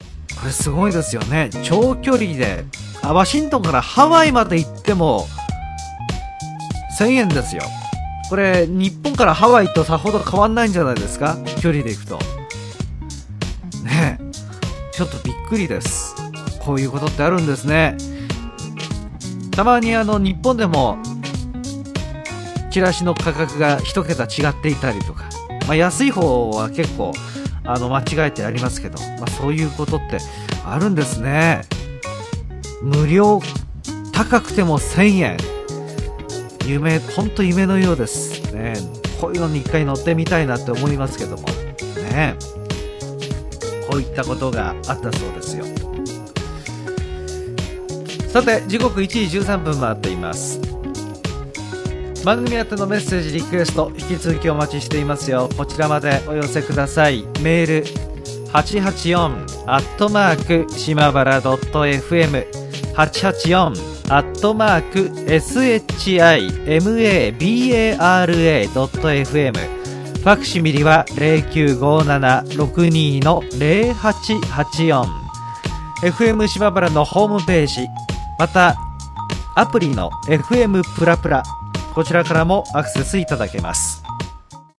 Slow Speed